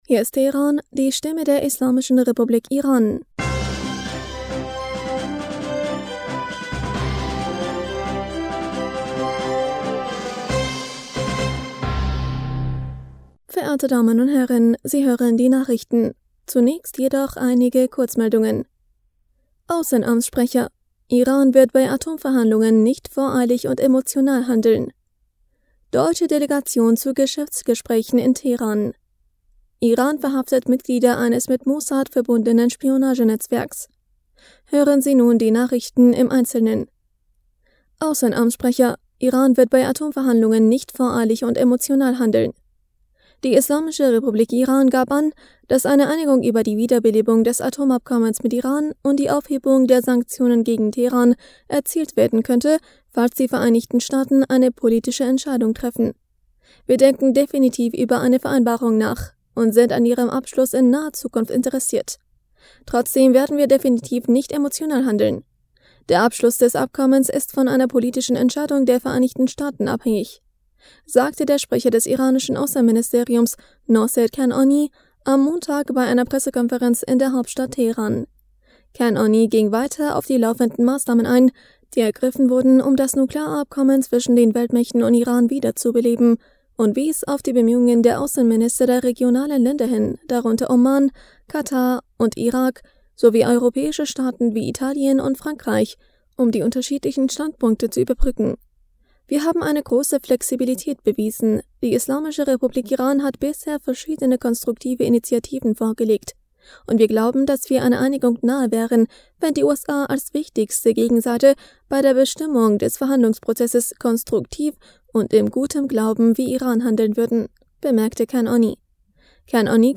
Nachrichten vom 25. Juli 2022